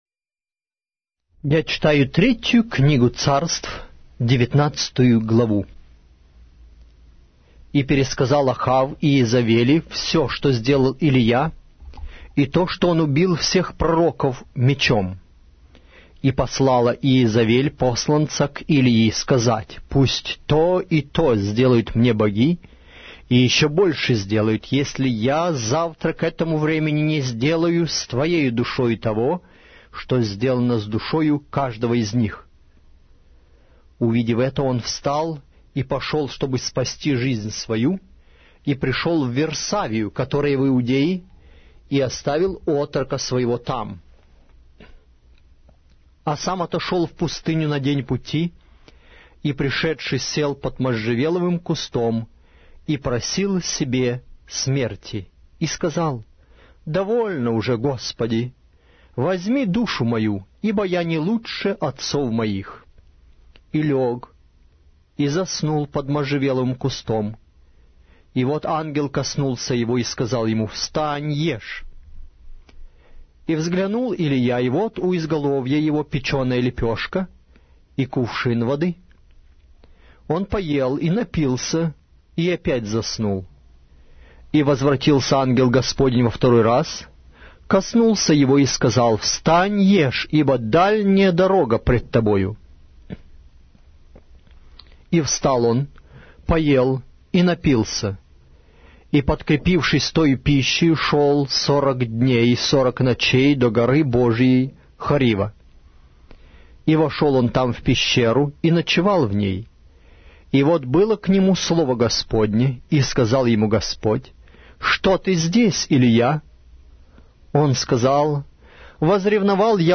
Аудиокнига: 3-я Книга Царств